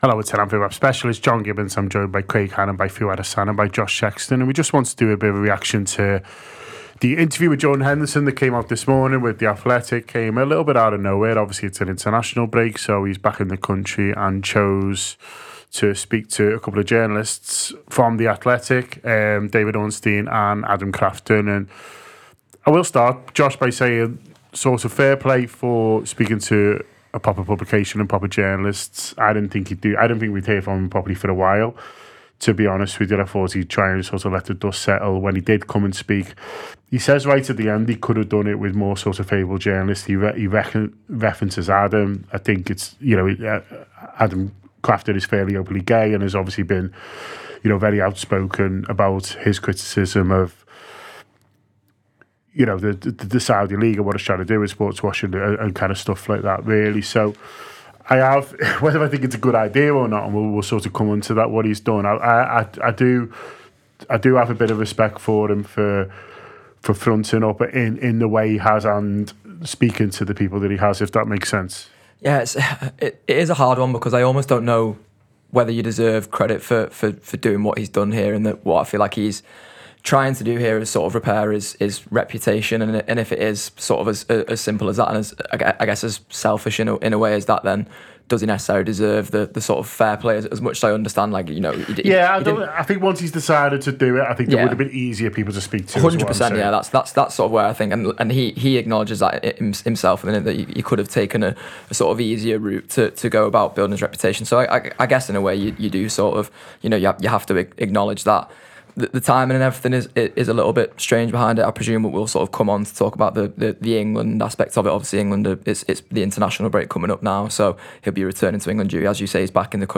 Below is a clip from the show – subscribe for more on Jordan Henderson’s interview with The Athletic…